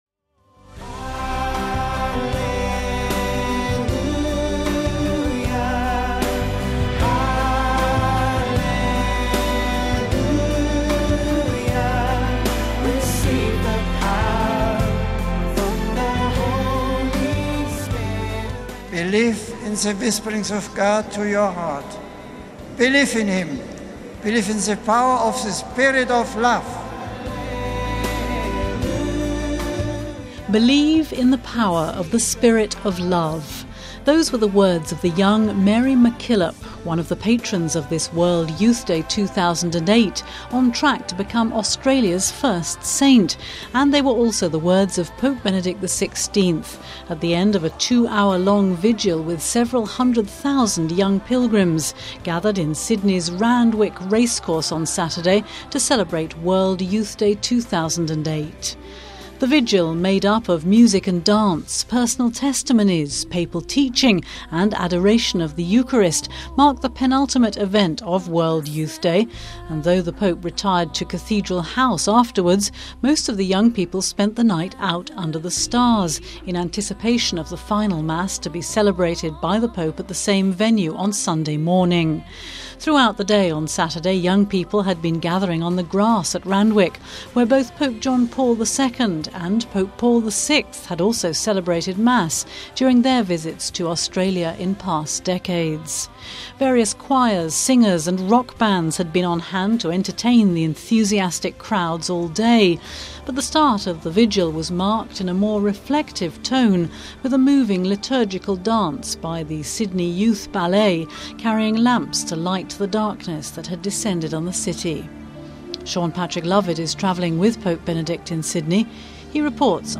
Home Archivio 2008-07-19 17:13:40 WITNESS THE SPIRIT Pope Benedict leads a giant youth vigil at Sydney's Randwick Racecourse telling young pilgrims to 'believe in the power of the spirit of love'....